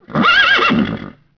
horsew04.wav